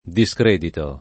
[ di S kr % dito ]